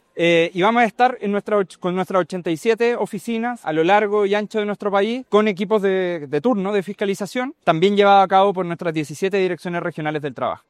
En este sentido, el director nacional (s) del Trabajo, Sergio Santibáñez, detalló que habrá un importante despliegue a lo largo del país para fiscalizar que se cumpla esta normativa.
cuna-comercio-director-trabajo.mp3